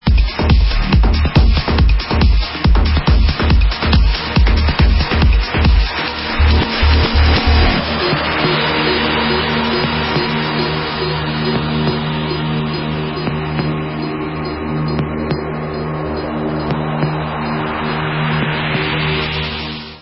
sledovat novinky v oddělení Dance/Trance